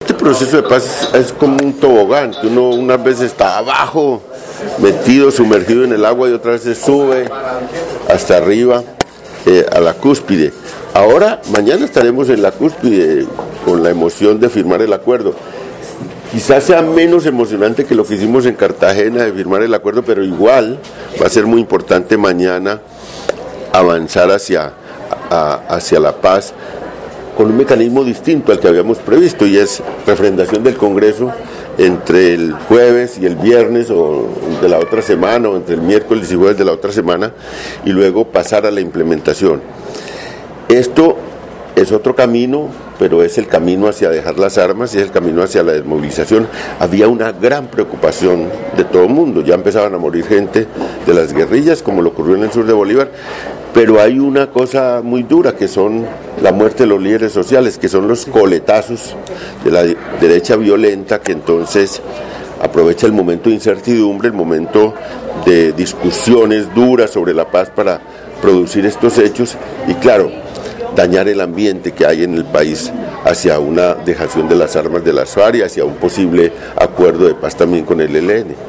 Valencia Agudelo asistió en esta capital al evento Comunicación y Convivencia, Instrumento para una Paz Estable y Duradera, en el que además dijo que la refrendación del nuevo acuerdo con las Farc a través del Congreso es un mecanismo distinto al que estaba previsto, pero es el camino para dejar las armas y lograr la paz.